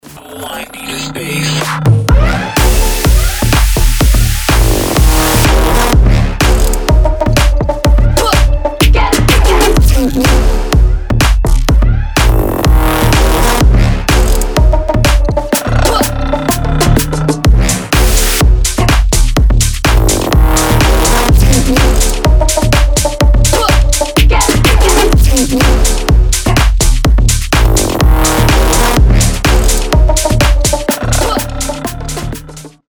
• Качество: 320, Stereo
EDM
Bass House
Стиль: Brohouse